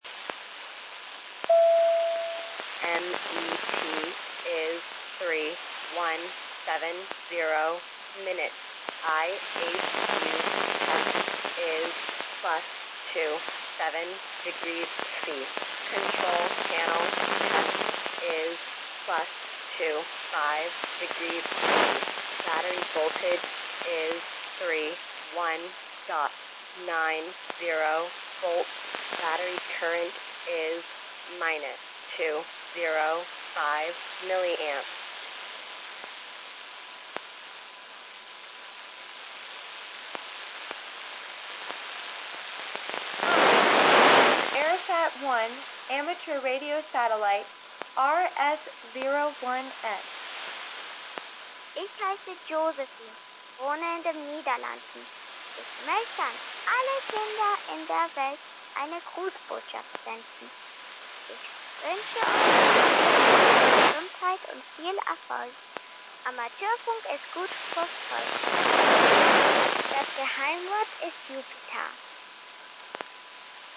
голос ARISS1